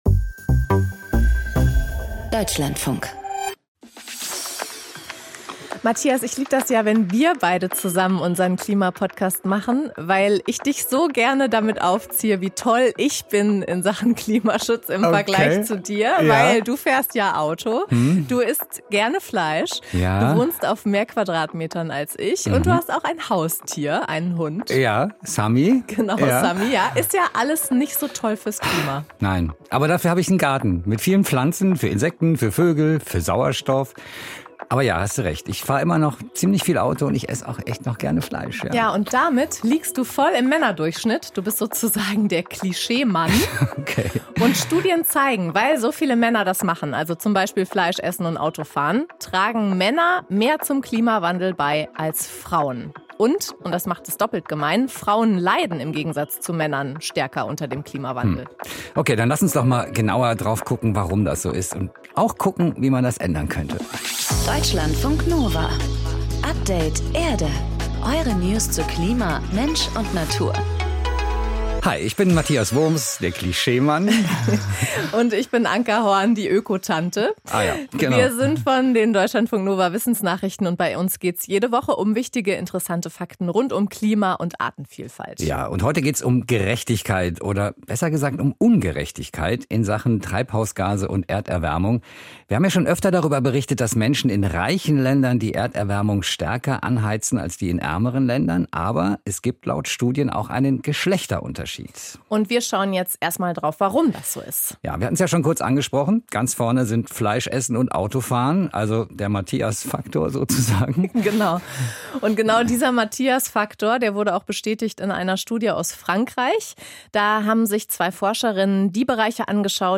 Aus dem Podcast Interview Podcast abonnieren Podcast hören Podcast Interview Das Interview im Deutschlandfunk Kultur greift kulturelle und politische Trends ebenso auf wie...